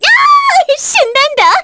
Epis-Vox_Victory_kr.wav